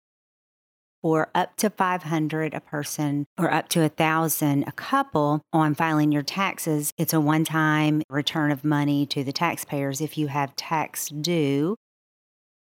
2. Senator Thompson Rehder adds Missouri senators put a one-time tax refund to Missourians into House Bill 2090.